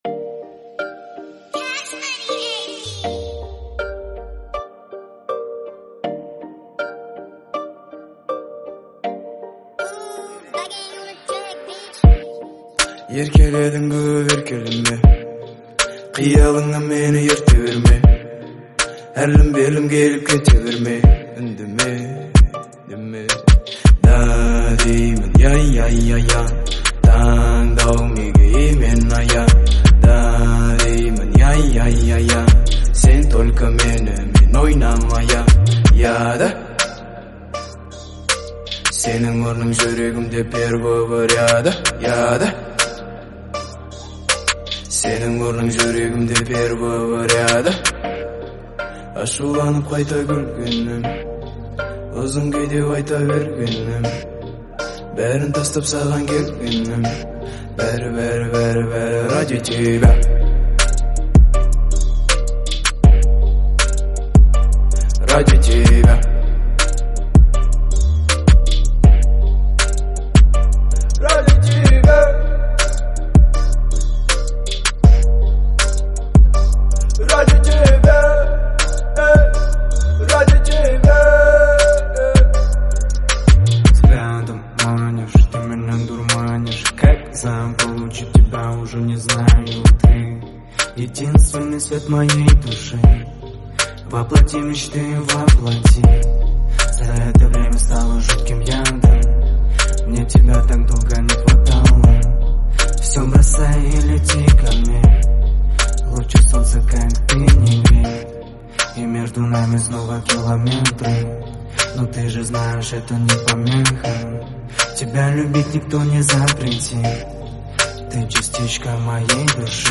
это эмоциональная композиция в жанре поп